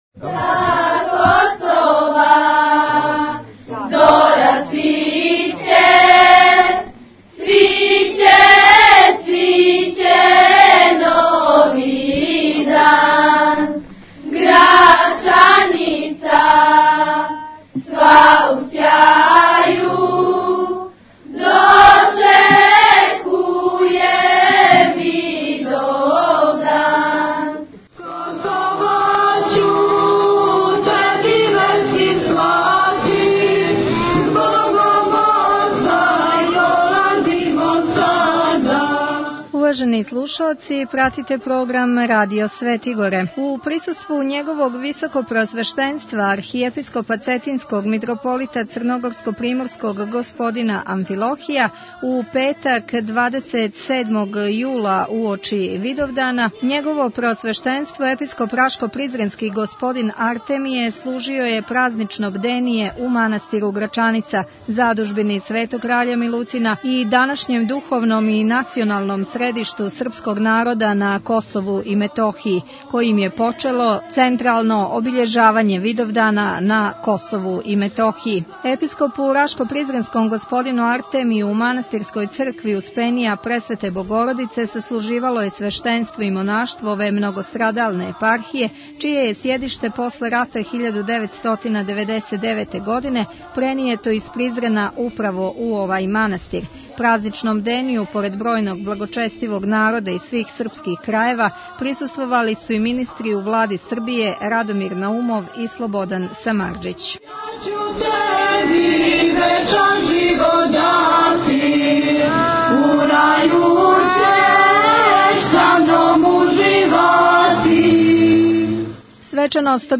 Видовдан у Грачаници и на Газиместану Tagged: Из наше Цркве 42:11 минута (7.24 МБ) Светом Архијерејсеком Литургијом у манастиру Грачаница и парастосом на Газиместану свима пострадалима до Косова, на Косову пољу и после Косова, које су служили Његово Високопреосвештенство Архиепископ Цетињски Митрополит Црногорско - приморски Г. Амфилохије и Преосвећена Господа Епископи Рашко - призренски и Косовско - метохијски Артемије и Липљански игуман Високих Дечана Теодосије прослављен је Видовдан на Косову и Метохији.